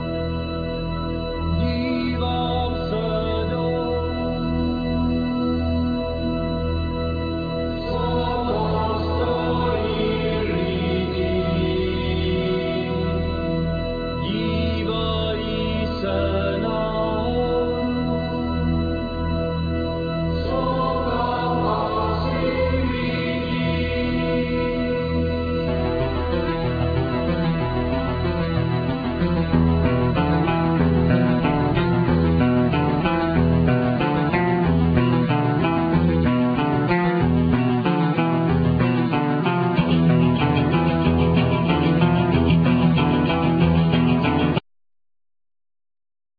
Accordion,Guitar,Vocals
Clarinet,Guitar,Vocals
Drums
Baritone&Tennor Sax